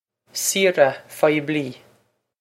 Pronunciation for how to say
see-ra fye-b-lee
This comes straight from our Bitesize Irish online course of Bitesize lessons.